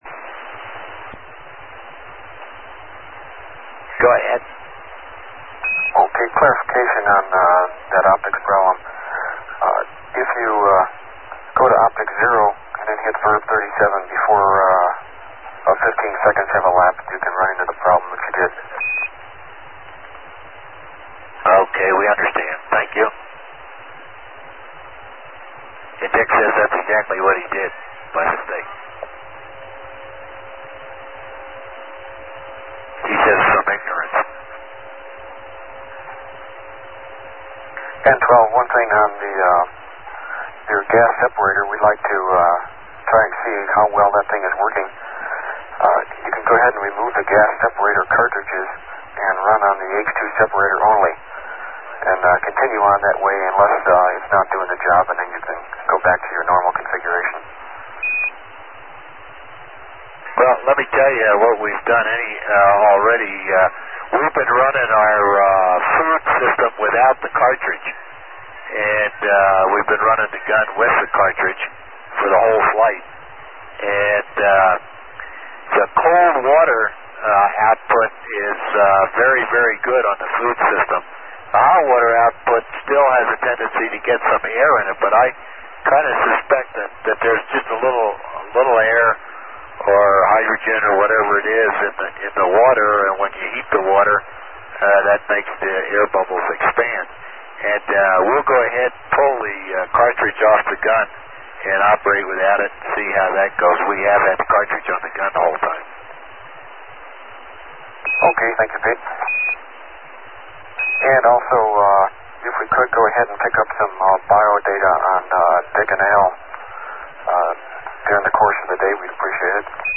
Scrivener tape, Australia.